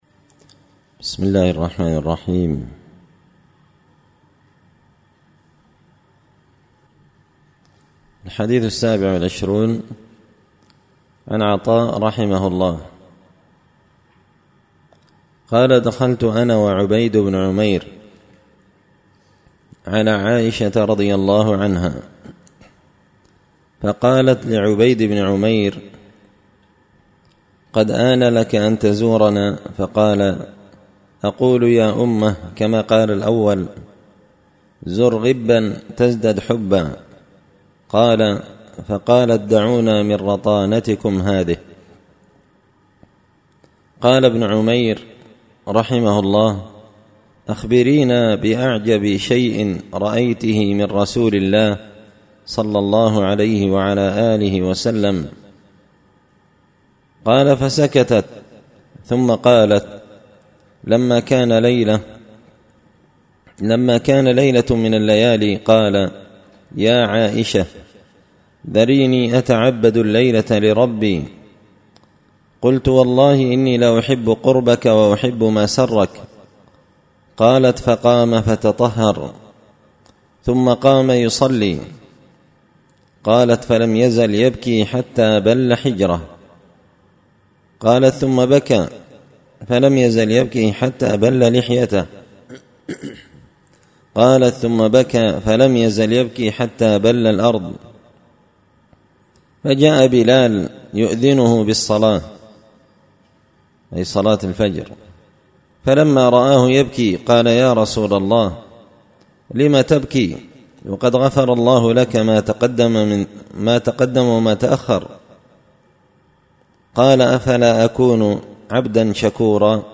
الأحاديث الحسان فيما صح من فضائل سور القرآن ـ الدرس الثالث والعشرون